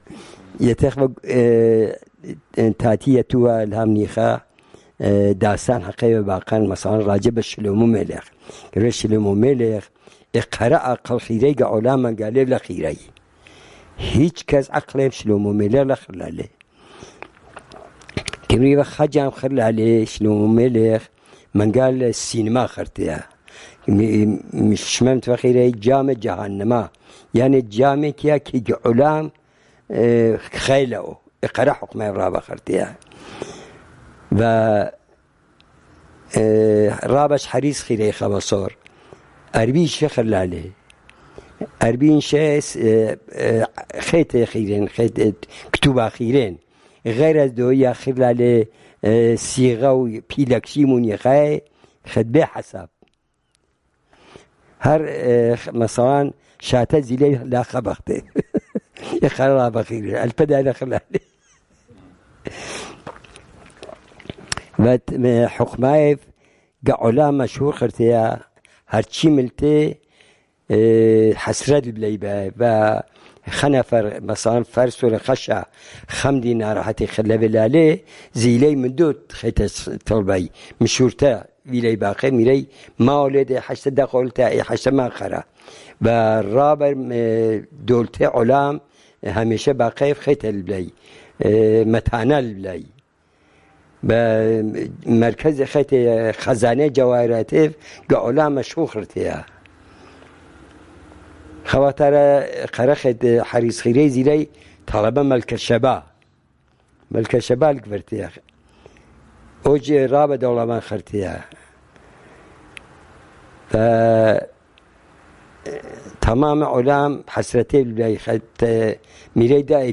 Sanandaj, Jewish: King Solomon